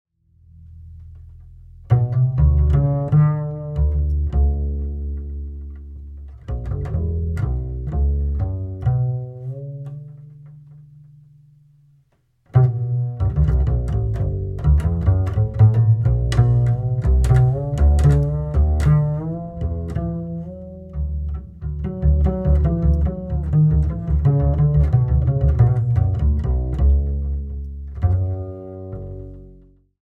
soprano sax, bassclarinet
accordion
double bass
drums